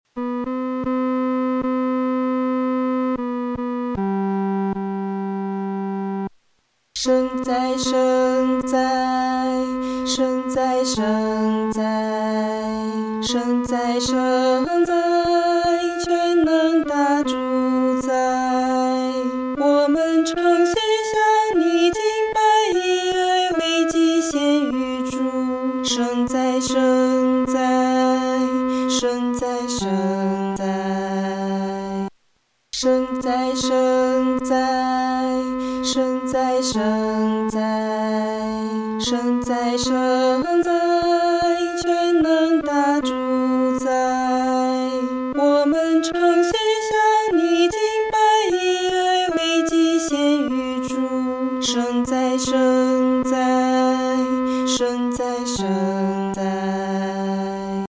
合唱
四声 下载